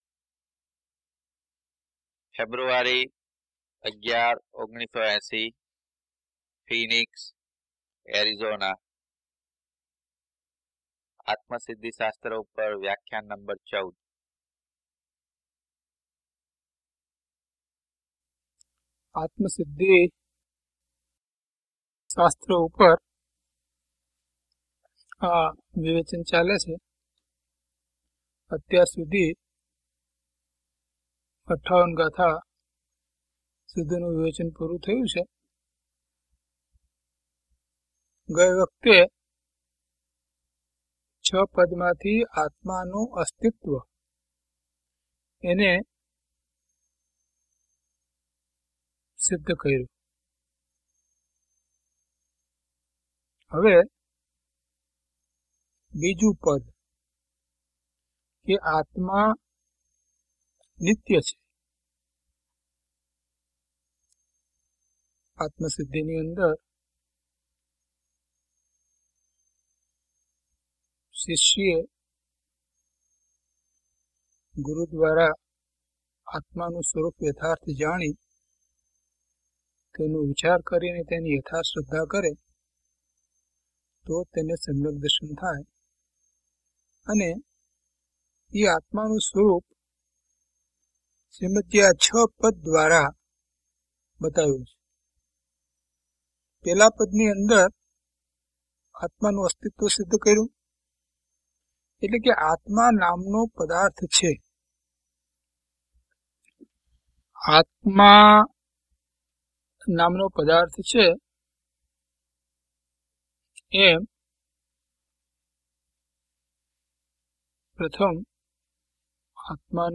DHP023 Atmasiddhi Vivechan 14 - Pravachan.mp3